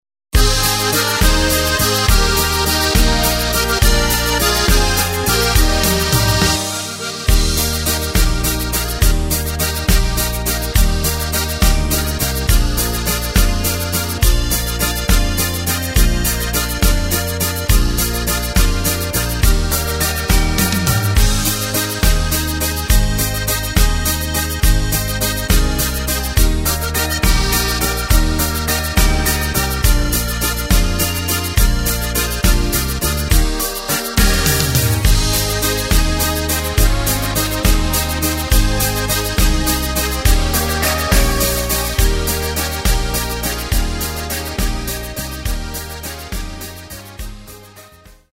Takt:          3/4
Tempo:         207.50
Tonart:            Eb
Walzer mit Polka-Ending aus dem Jahr 1999!
Playback mp3 mit Lyrics